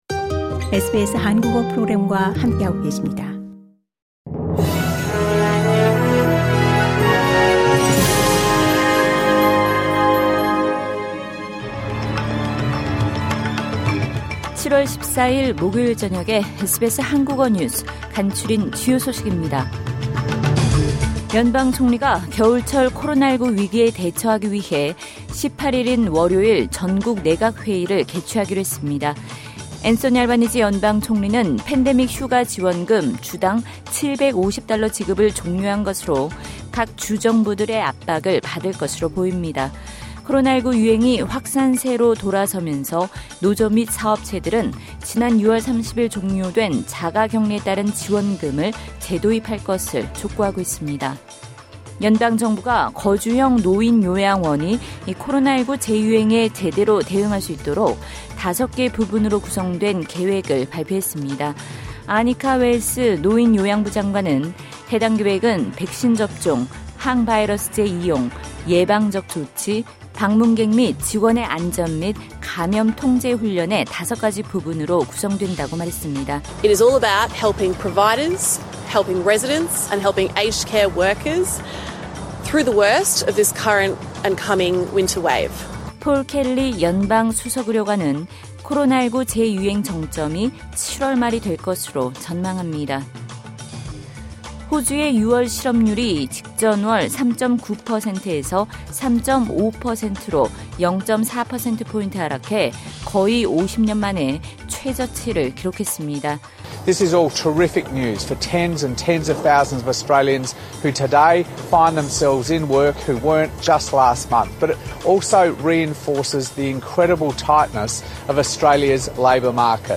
SBS 한국어 저녁 뉴스: 2022년 7월 14일 목요일